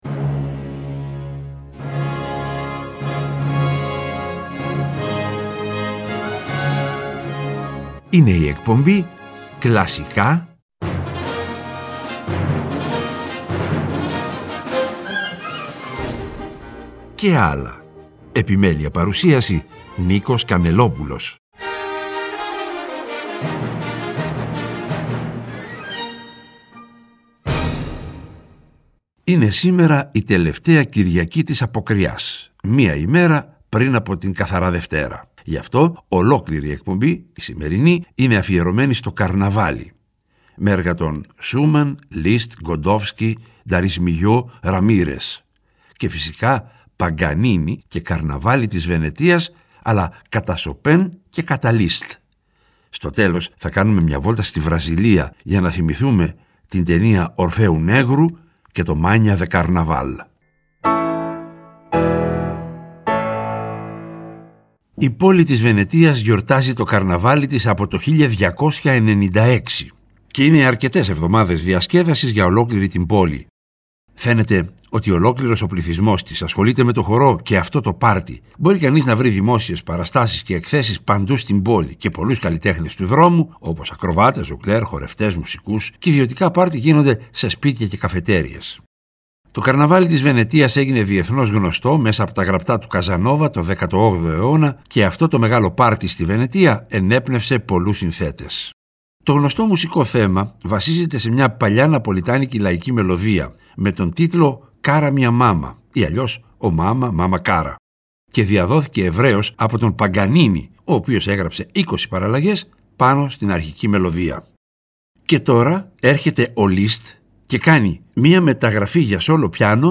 Και, προς το τέλος κάθε εκπομπής, θα ακούγονται τα… «άλλα» μουσικά είδη, όπως μιούζικαλ, μουσική του κινηματογράφου -κατά προτίμηση σε συμφωνική μορφή- διασκ